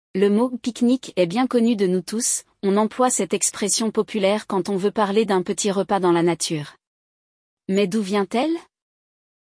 No episódio de hoje, um homem e uma mulher fazem planos para o fim de semana: um belo piquenique num parque da cidade!
Le Dialogue